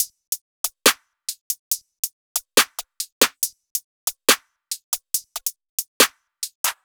MZ TL [UK Drill - 140BPM].wav